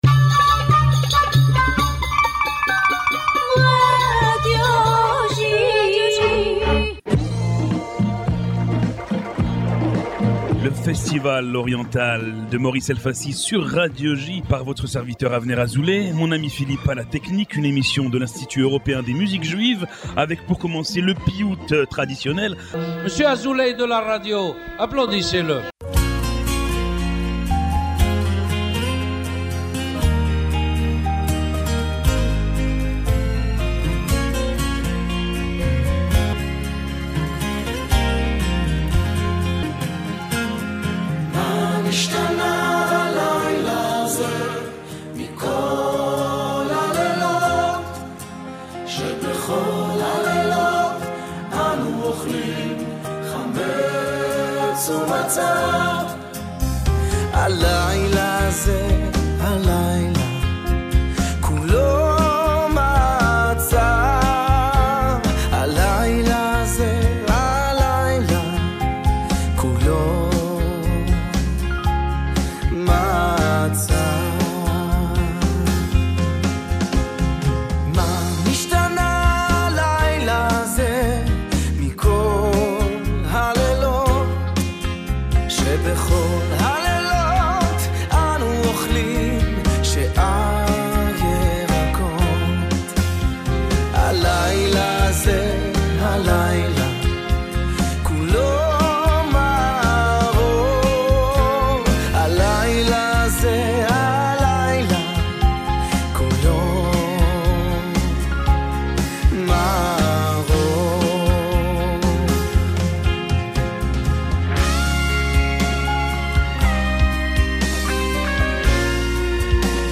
Diffusé chaque lundi sur les ondes de Radio J (94.8 FM), Le festival oriental est une émission de l’Institut Européen des Musiques Juives entièrement dédiée à la musique orientale.